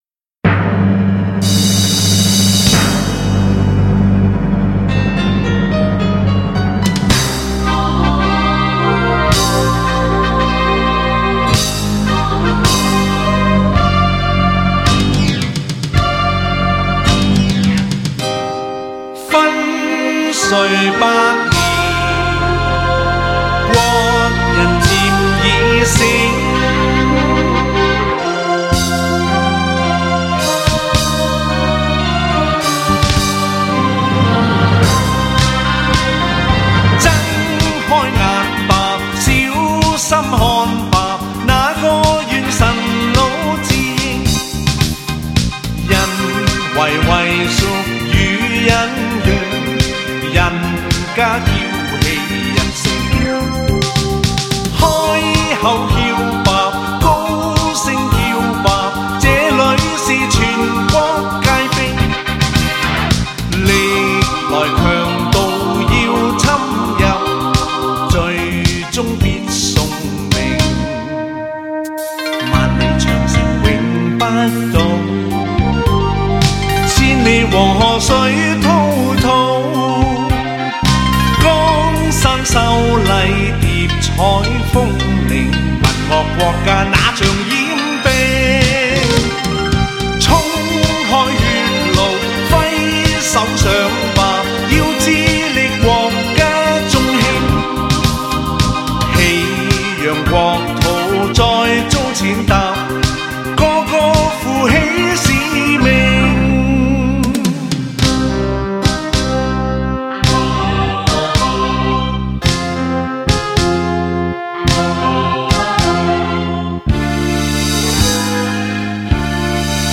在唱功方面，一向以运用“假音”的技巧闻名，而在歌曲的基调方面，则往往流于沧桑和悲凉。
高密度24BIT数码录音
歌词口语化，亲切自然，尤其以粤语演唱，铿锵有力，气势非凡。“